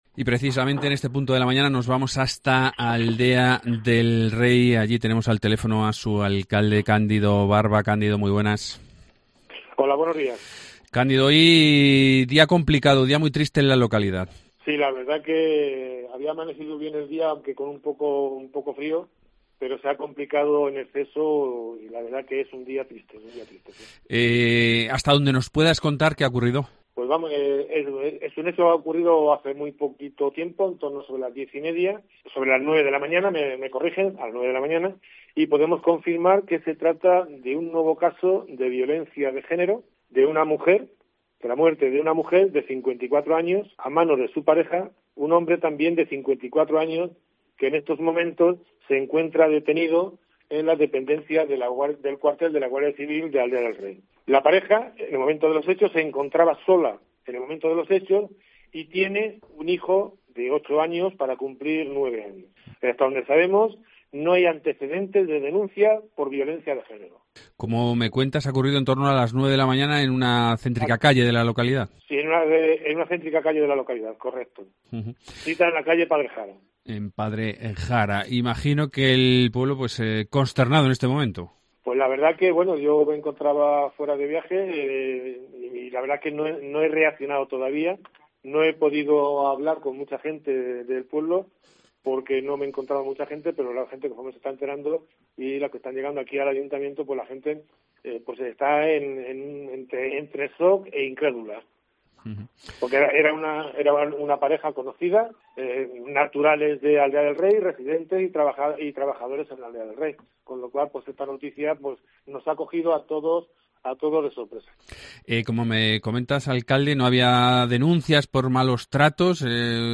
Entrevista con Cándido Barba, alcalde de Aldea del Rey